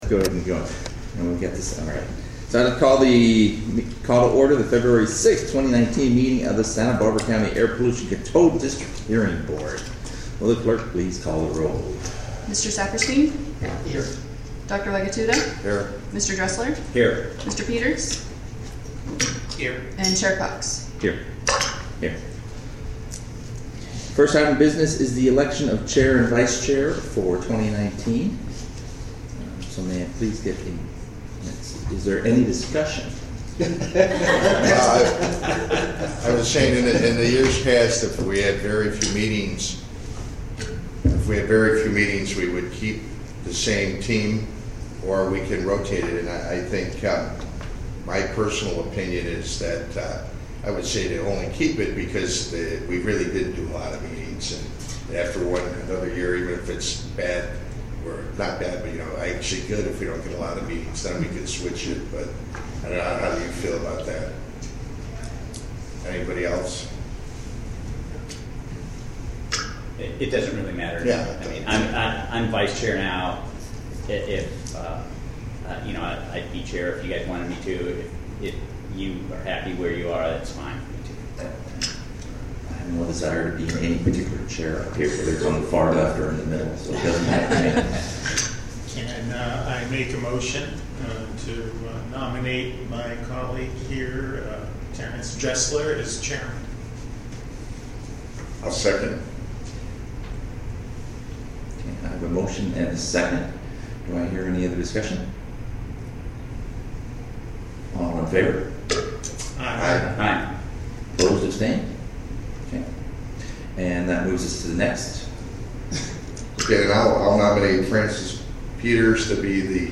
Agenda | Public Notice | Meeting Audio